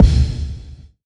tck_kick.wav